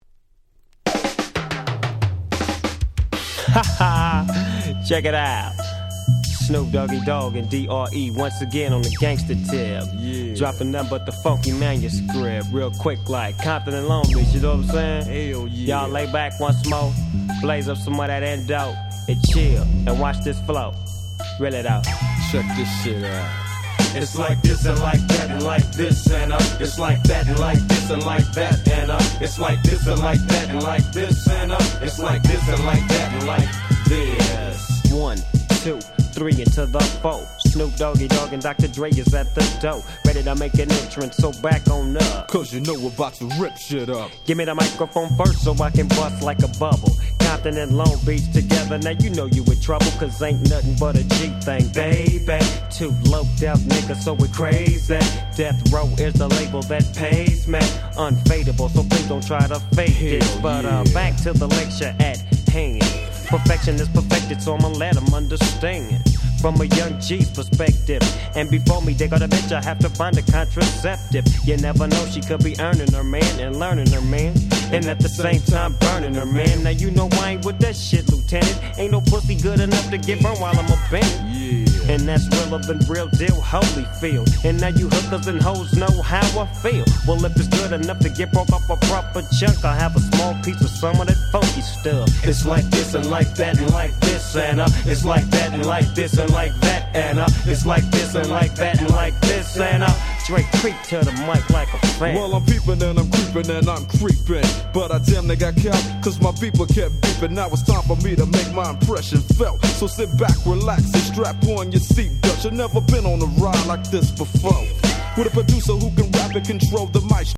93' Big Hit West Coast Hip Hop.